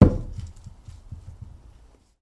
国内声音 " 大块的木头被扔下 01
描述：在一个水泥地板上下落的大槭树日志 用数字录音机录制并使用Audacity处理